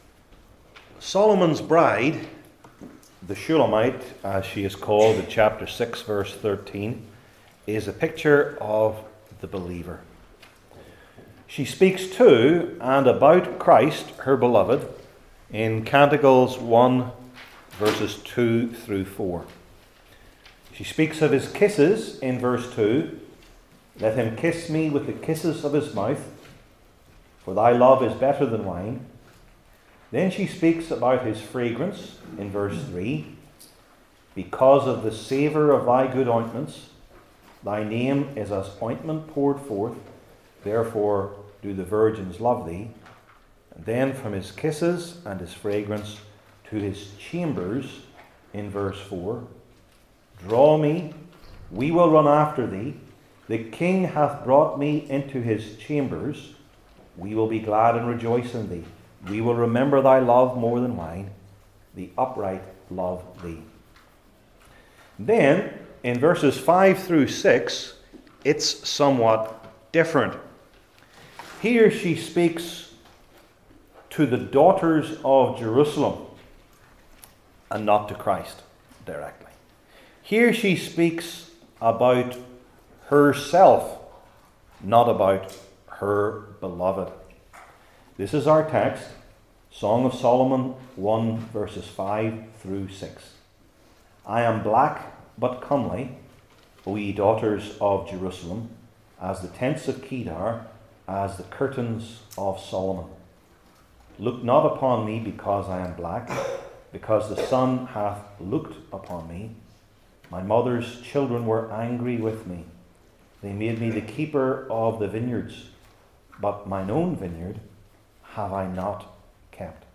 Old Testament Sermon Series I. The Confession II.